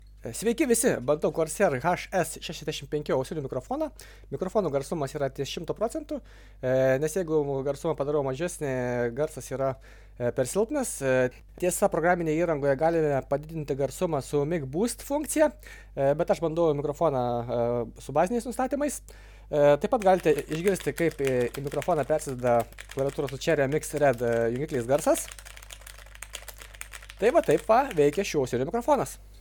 Mikrofonas
Iš garso įrašo darosi aišku, kad tai nėra pats garsiausias mikrofonas, bet įrašomo garso kokybė yra tikrai gera.
Corsai-HS65-mic.mp3